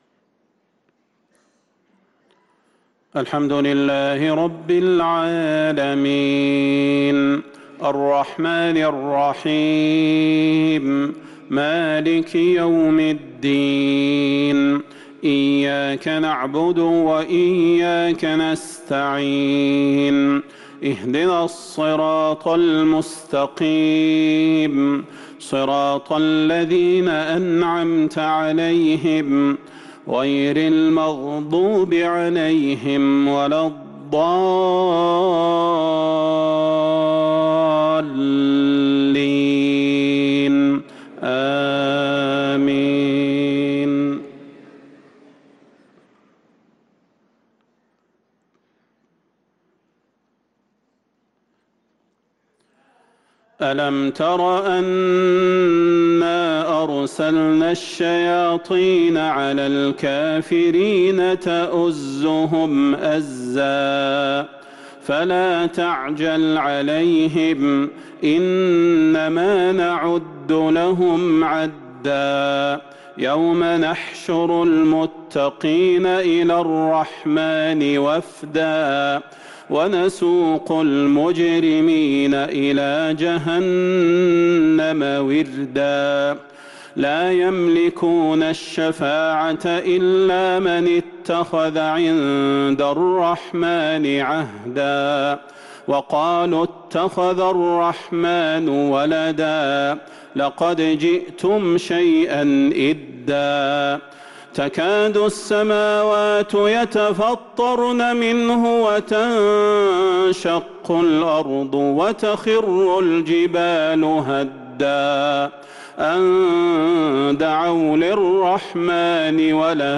صلاة المغرب للقارئ صلاح البدير 19 جمادي الأول 1444 هـ